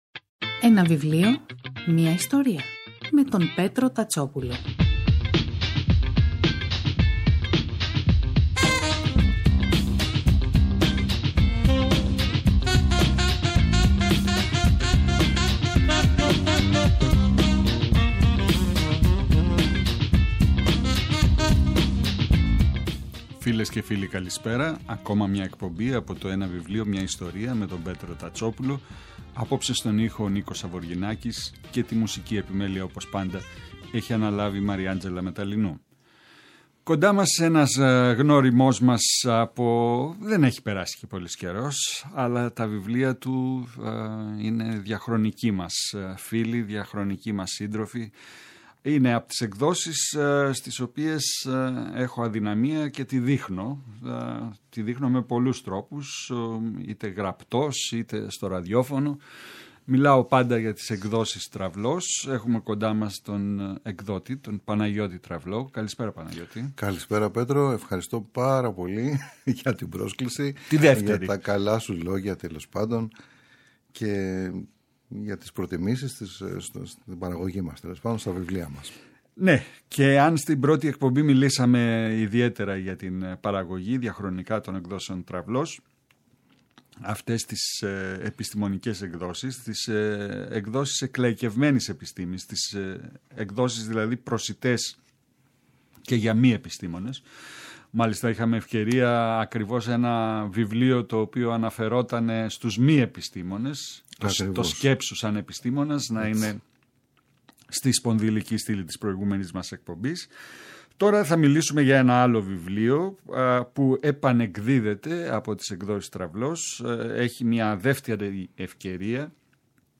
Η εκπομπή “Ένα βιβλίο, μια ιστορία” του Πέτρου Τατσόπουλου , κάθε Σάββατο και Κυριακή, στις 5 το απόγευμα στο Πρώτο Πρόγραμμα της Ελληνικής Ραδιοφωνίας παρουσιάζει ένα συγγραφικό έργο, με έμφαση στην τρέχουσα εκδοτική παραγωγή, αλλά και παλαιότερες εκδόσεις.